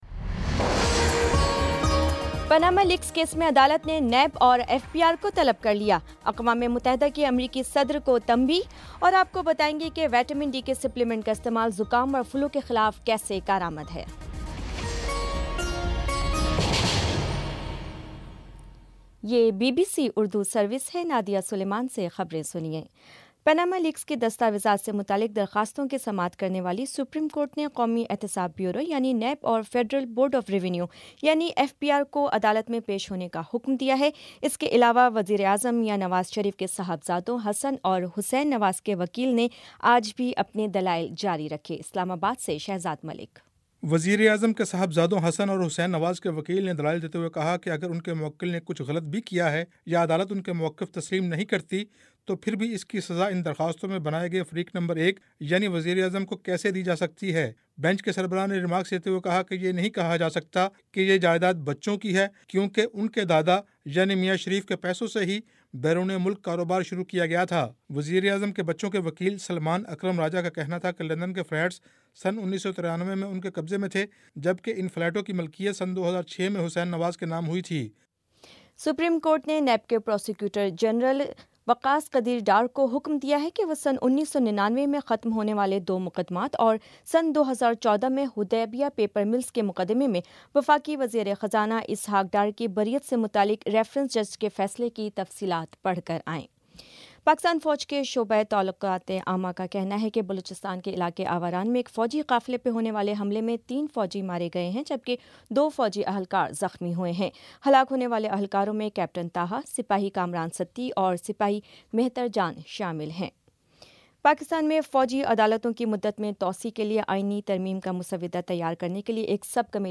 فروری 16 : شام چھ بجے کا نیوز بُلیٹن